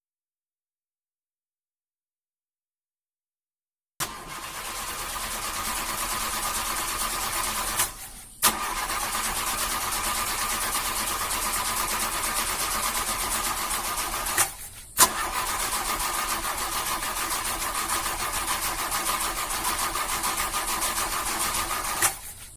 Engine Won't Start
Simulating an ICE engine trying unsuccessfully to start.